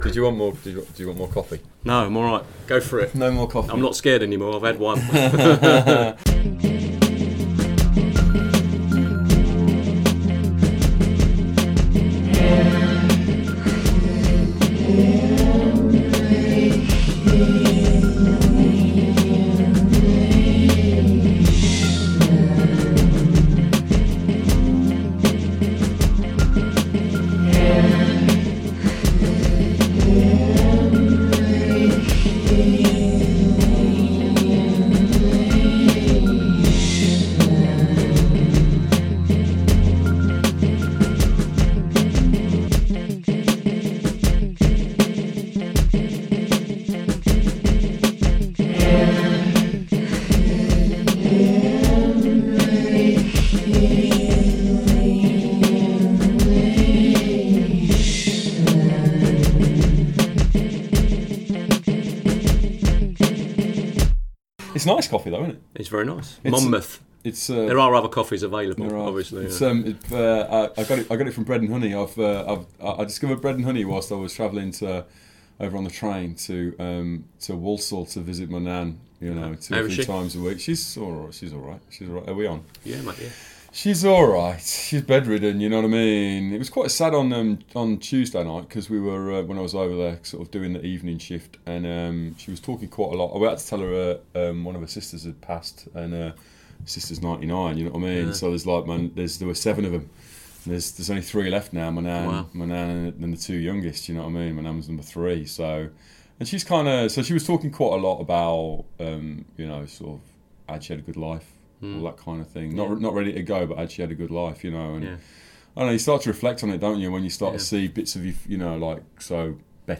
*Parental Advisory* between 17 and 18 minutes there is some swearing, as we discuss interactions following an English Defence League demonstration in Leicester in 2010.